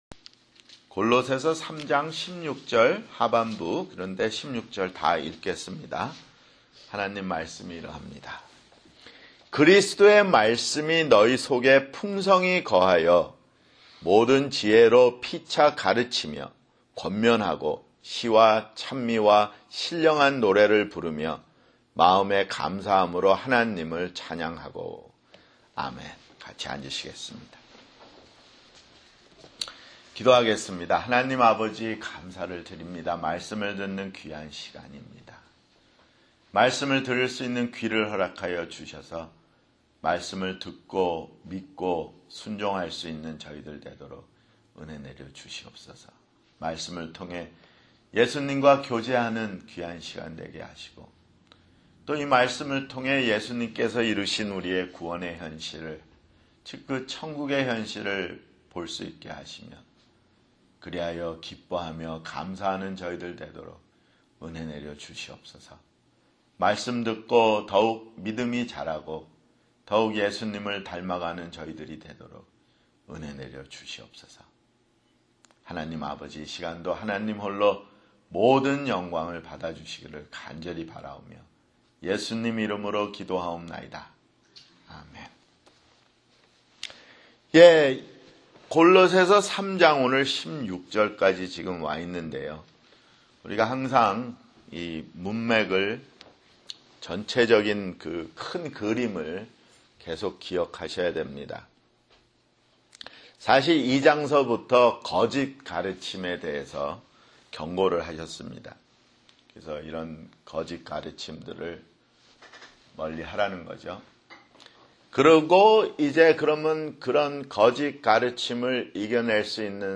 [주일설교] 골로새서 (71)